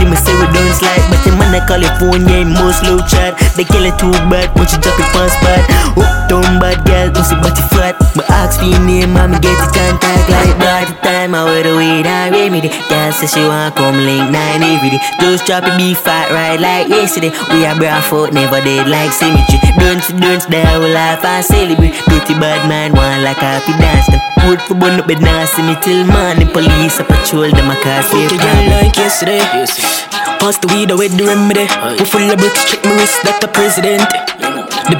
Жанр: Реггетон